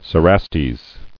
[ce·ras·tes]